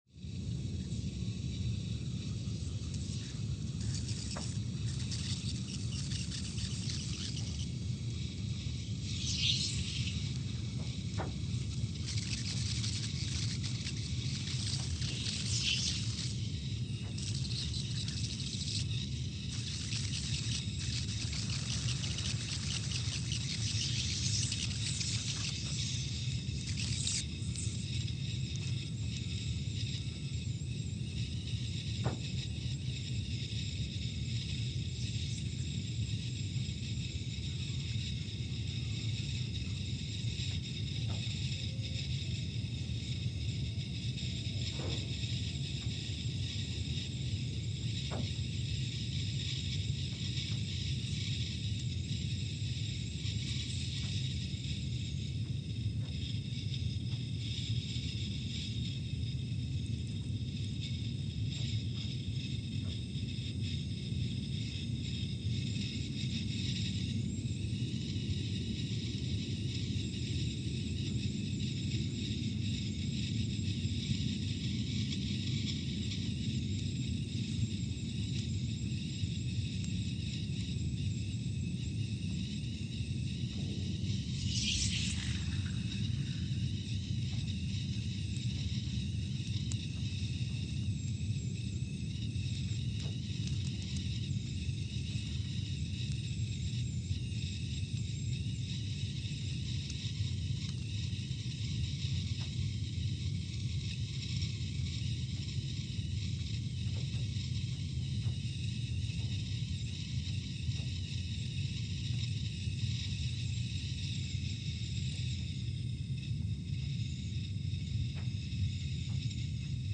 Scott Base, Antarctica (seismic) archived on October 12, 2019
No events.
Station : SBA (network: IRIS/USGS) at Scott Base, Antarctica
Sensor : CMG3-T
Speedup : ×500 (transposed up about 9 octaves)
Loop duration (audio) : 05:45 (stereo)